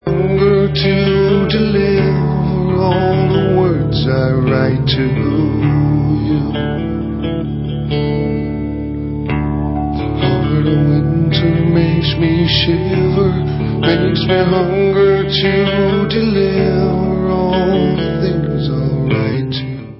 Allstar dutch rockband w/ex-anouk & kane members